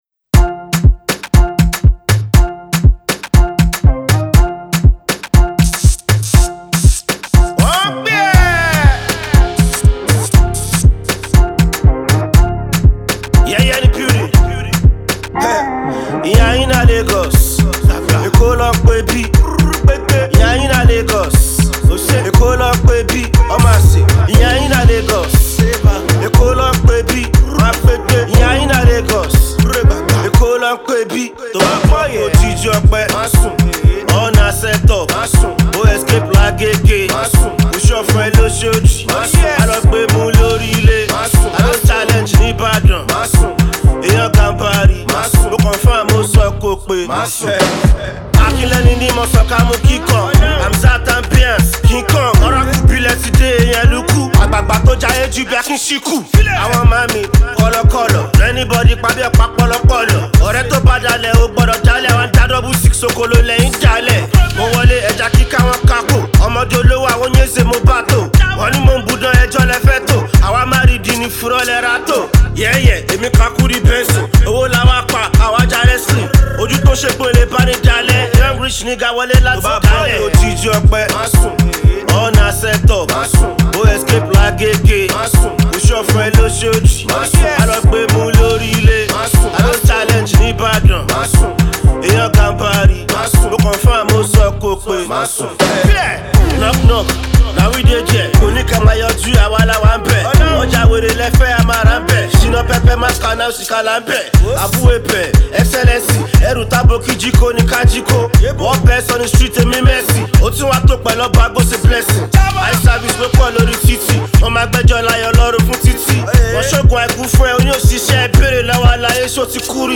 conscious banger